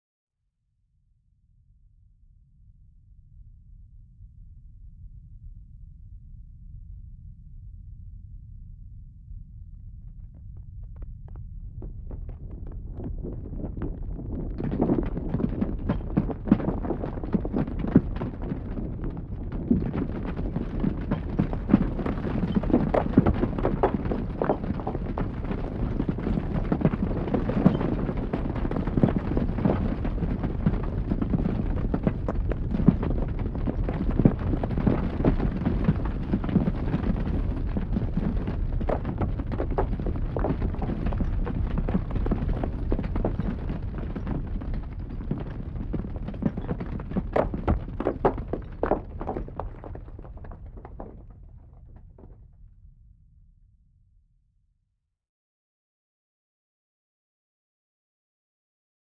Здесь собраны разные варианты: от далеких раскатов до близкого схода снежной массы.
Грохот сходящей лавины в горах